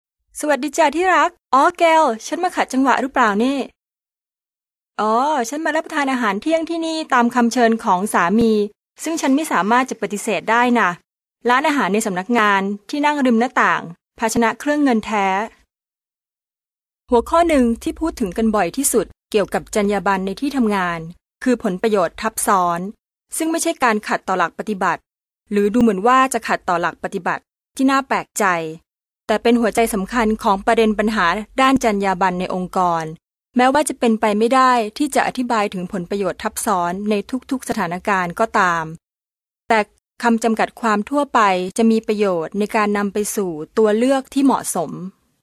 Sprecherin thailändisch für TV / Rundfunk /Industrie.
Sprechprobe: Industrie (Muttersprache):
Professionell female thai voice over artist